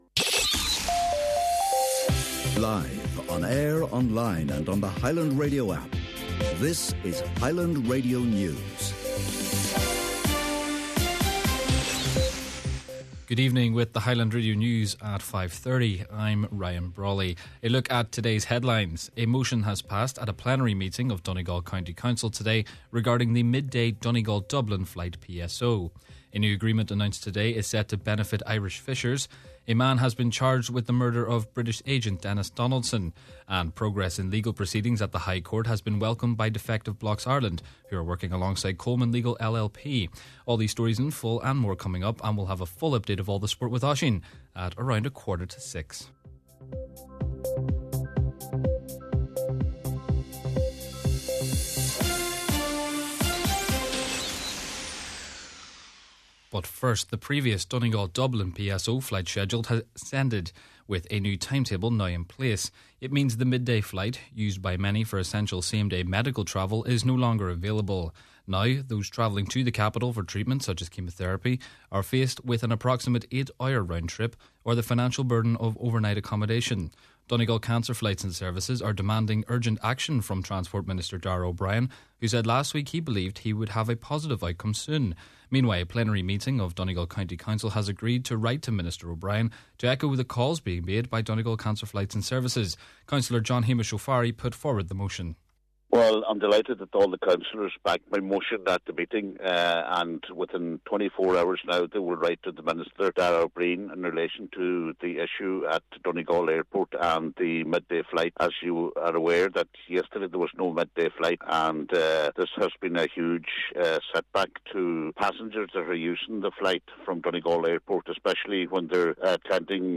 Main Evening News, Sport, and Obituary Notices – Monday, March 30th